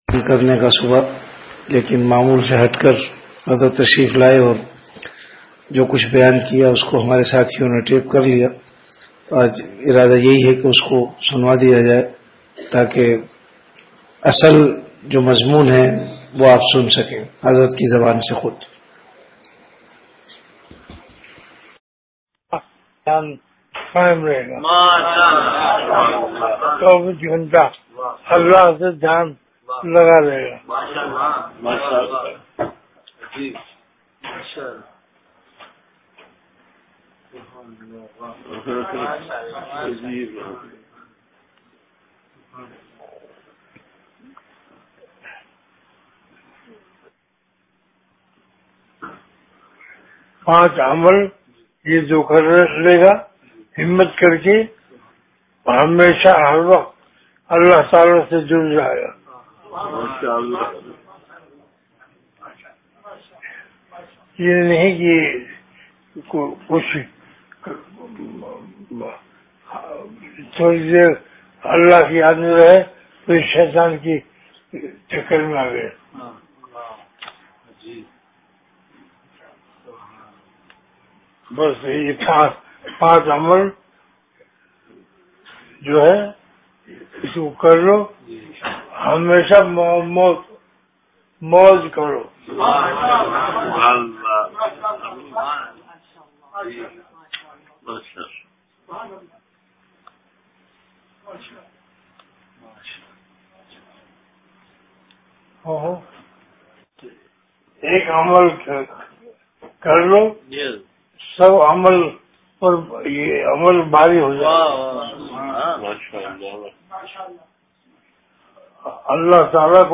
Delivered at Home.
Majlis e Zikr(Sun-22Nov2009)
After Isha Prayer